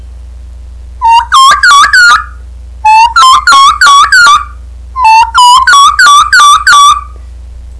Turkey Sounds